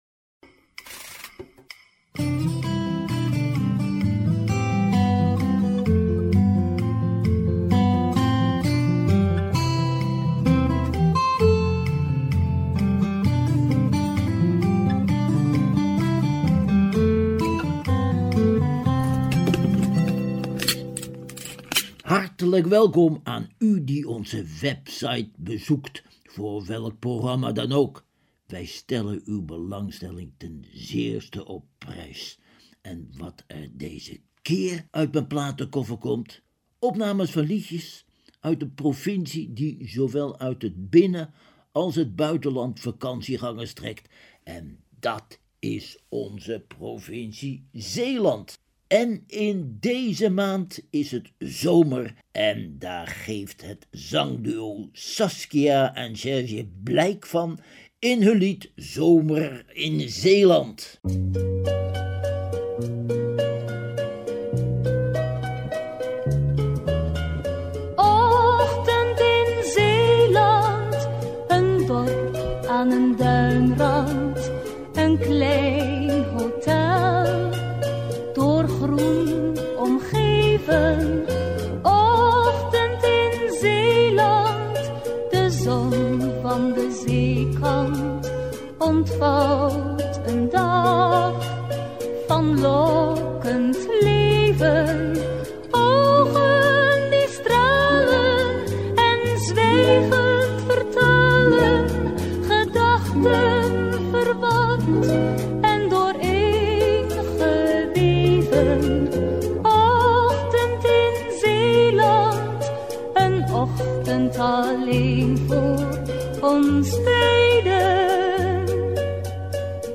Ook liedjes gezongen in dialect bijvoorbeeld over de zelfs voorbij de provinciale grenzen bekende lekkernijen komen voorbij.